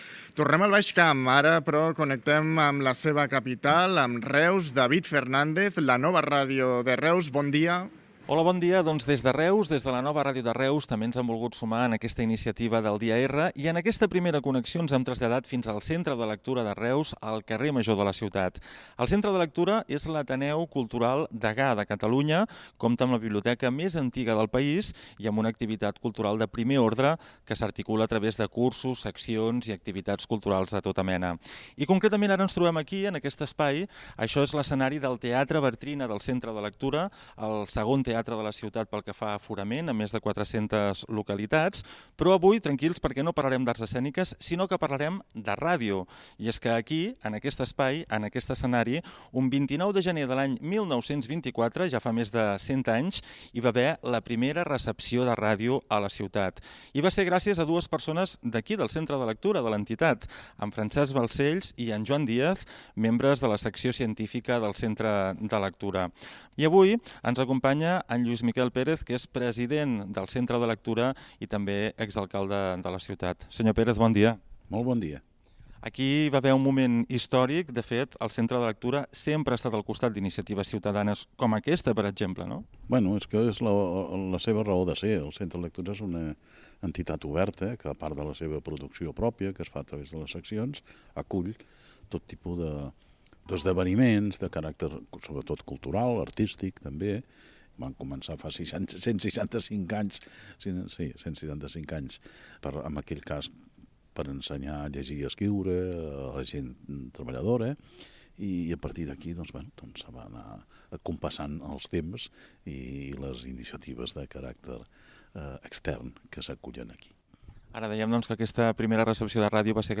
Connexió amb el centre de cultura de l'Ateneu de Reus on el 29 de gener de 1924 es va rebre la primera transmissió radiofònica. Entrevista a Lluís Miquel Pérez, president del centre de cultura i ex alcalde de Reus.
Divulgació